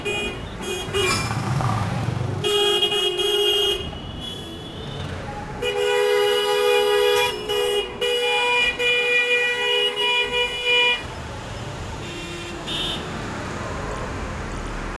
a-south-asian-street-with-6i5z4fnl.wav